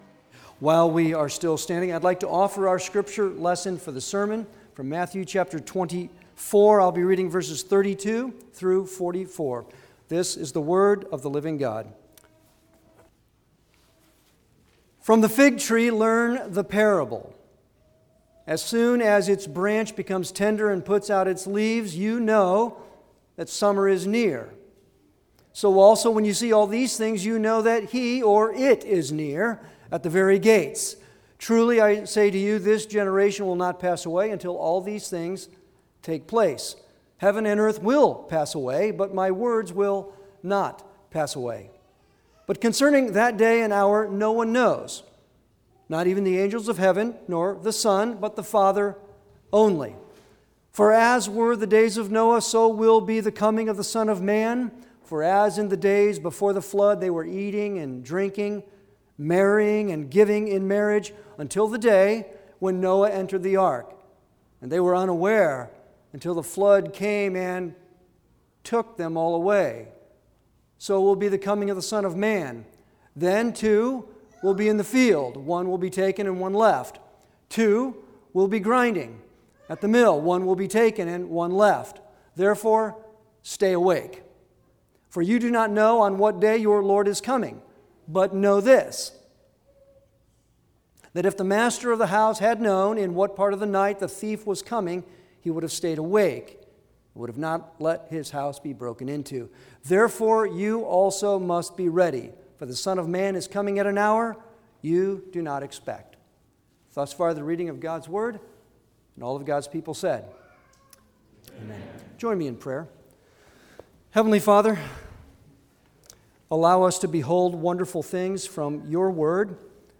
Series: Sermons on the Gospel of Matthew Passage: Matthew 24:32-44 Service Type: Sunday worship